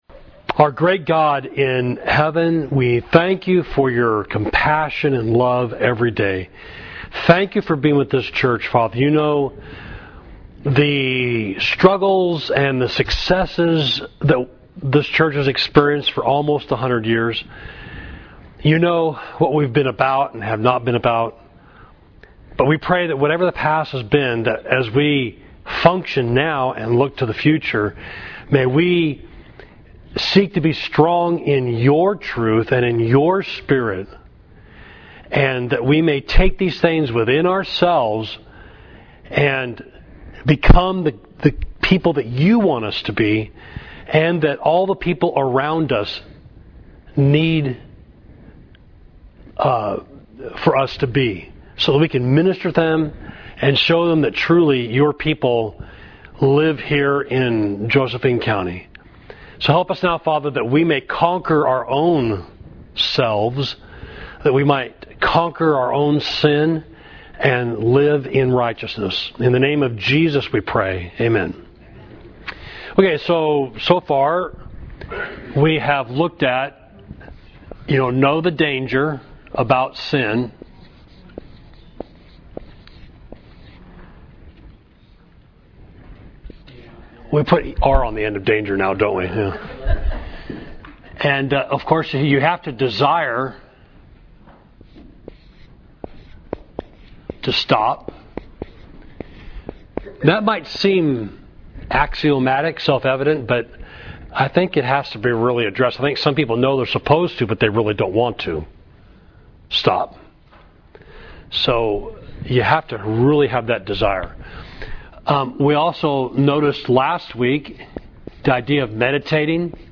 Date May 19, 2017 In relation to Adult Wednesday Class Class: Help!